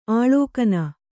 ♪ āḷōkana